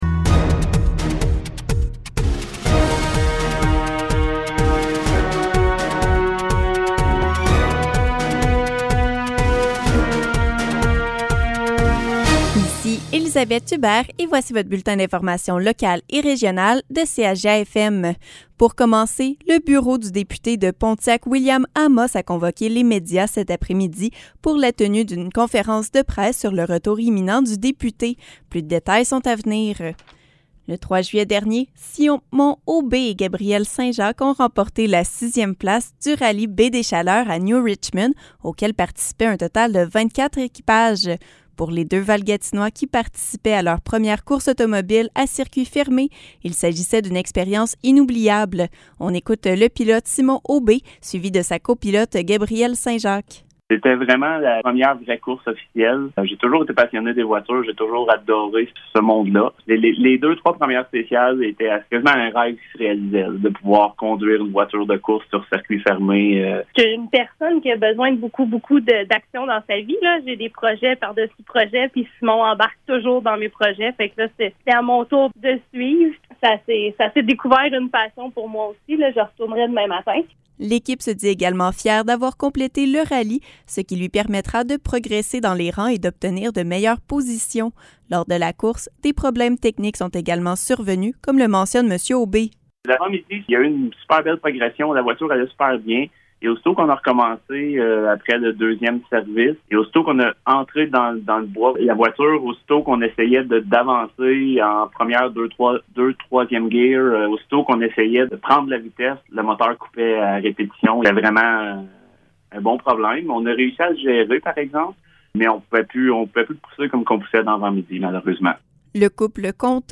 Nouvelles locales - 7 juillet 2021 - 12 h